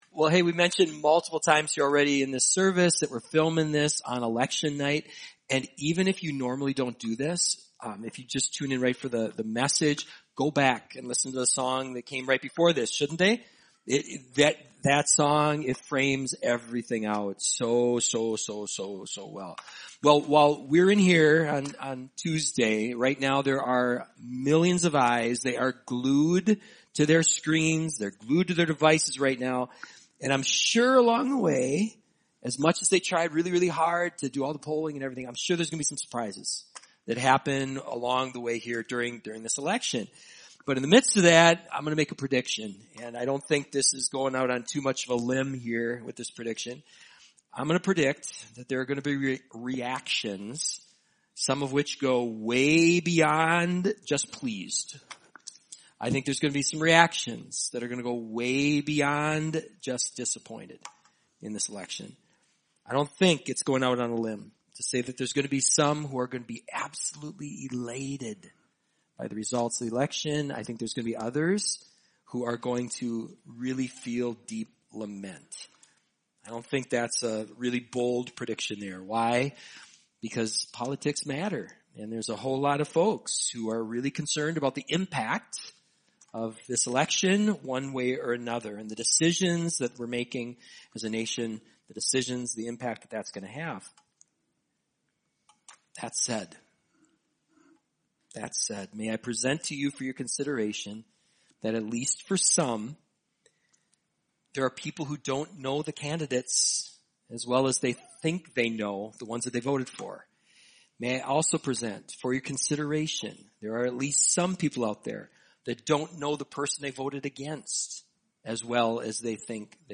Messiahs Higher Places Watch Message By